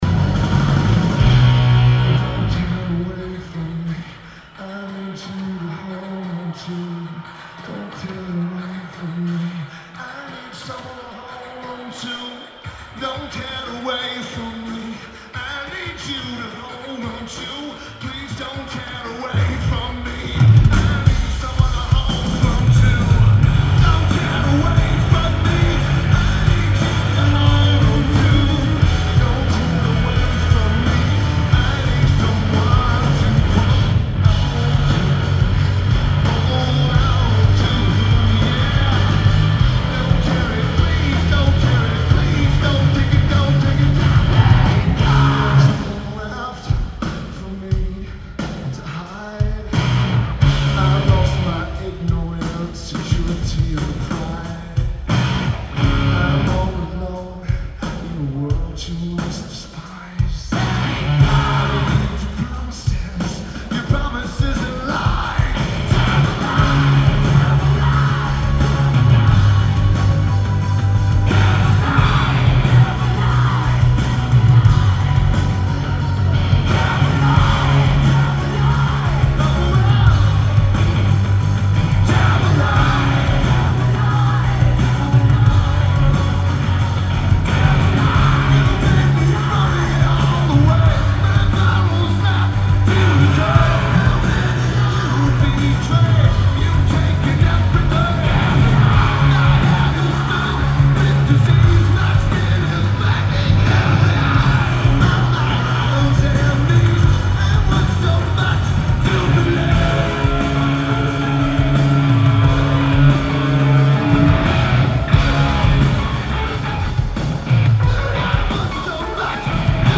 Oracle Arena